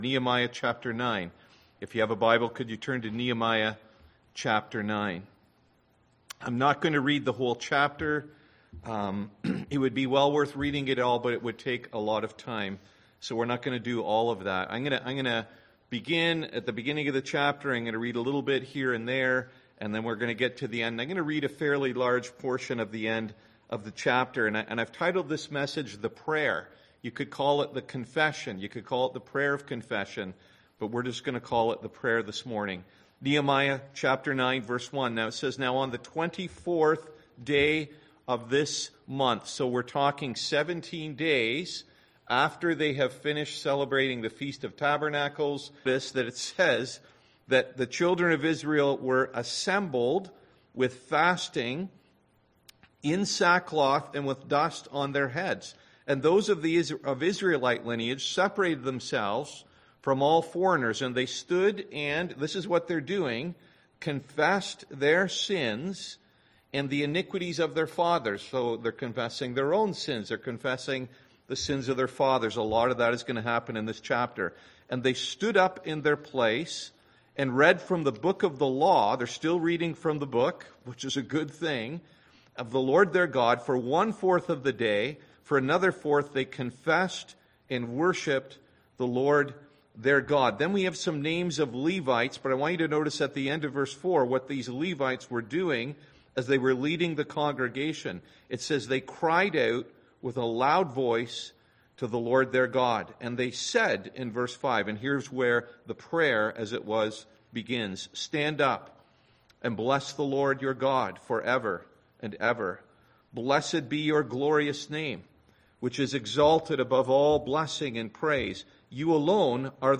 Service Type: Sunday AM Topics: Confession